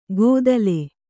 ♪ gōdale